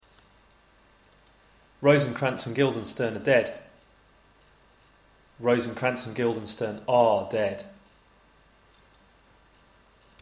Again, listen for the differences, and particularly the change of the sounds in are from [ə] to [ɑ:].